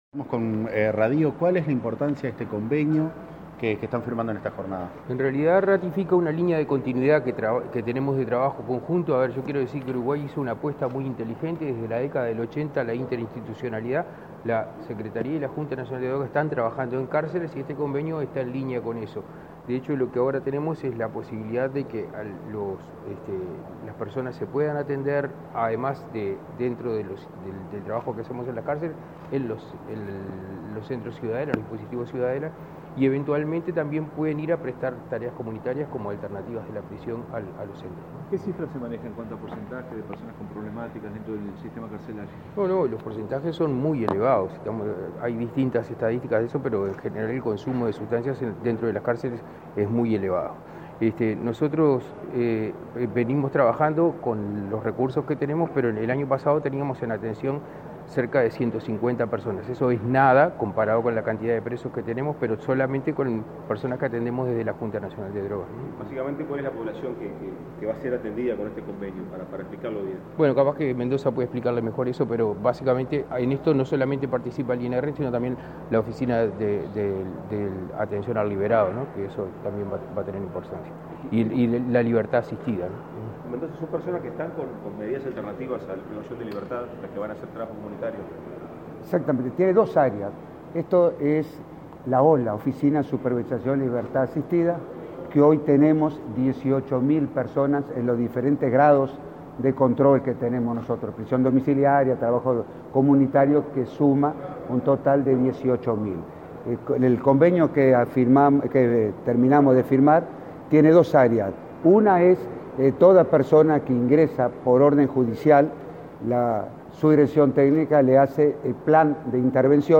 Declaraciones del secretario de la JND, Daniel Radío, y el responsable del INR, Luis Mendoza